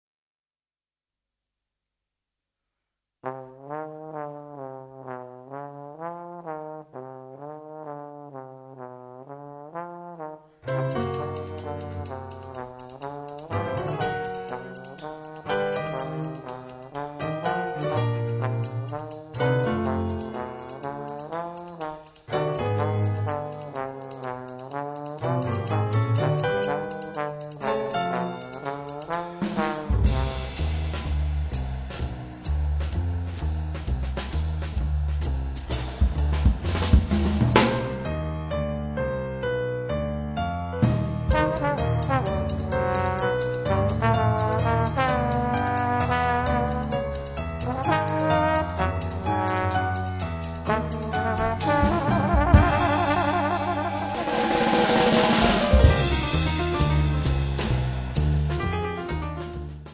Trombone
piano
contrabbasso
batteria